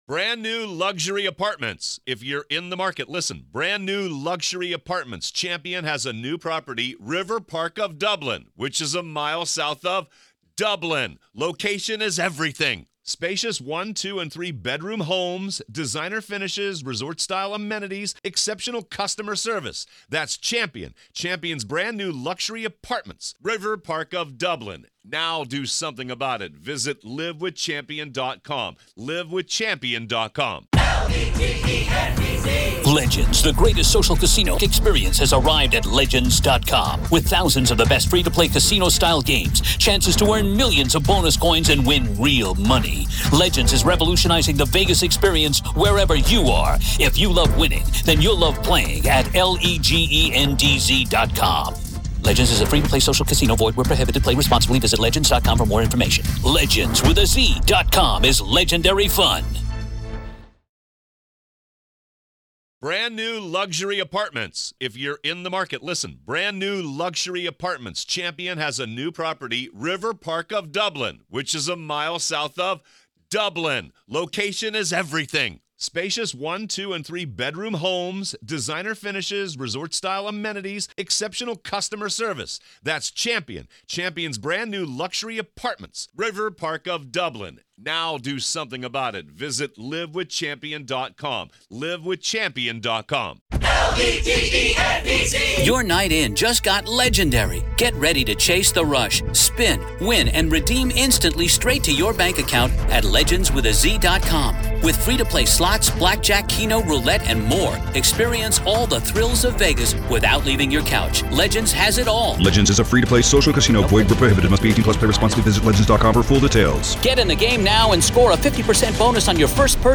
This is our continuing coverage of the Alex Murdaugh murder trial.